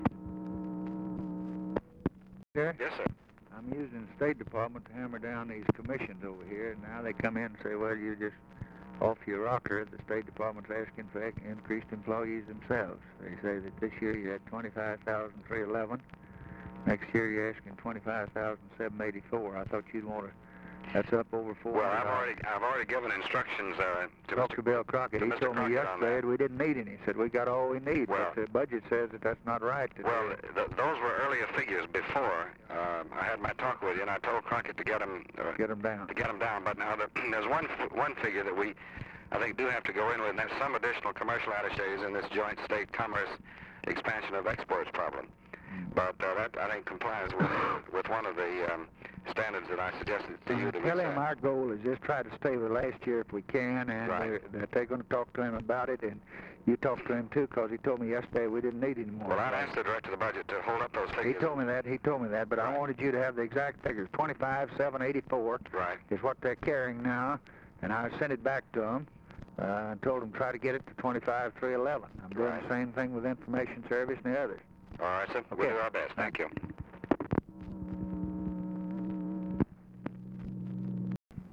Conversation with DEAN RUSK, December 10, 1963
Secret White House Tapes